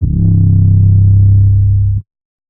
808_Oneshot_Costly_C
808_Oneshot_Costly_C.wav